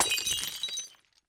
glass-breaking-sound